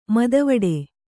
♪ madavaḍe